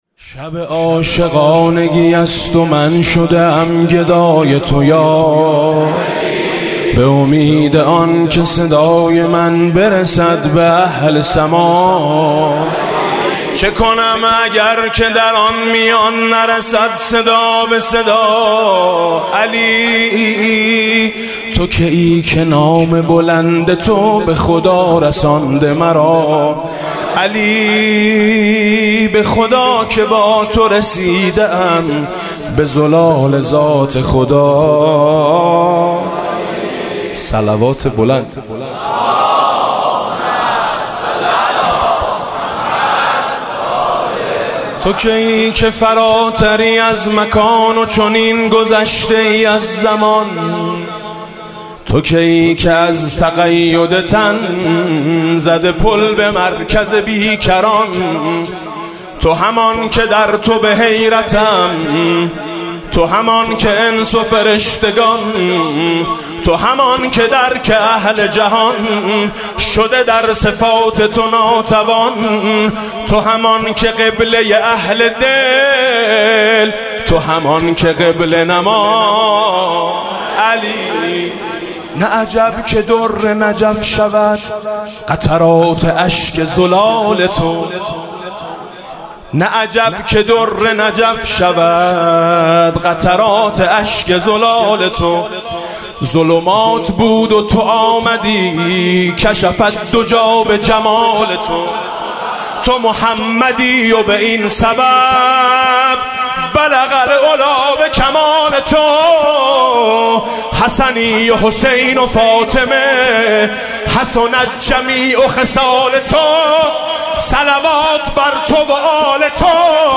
مدح: لِتُرابِ مَقدَمِکَ الفِدا دل و جان عاشق ما علی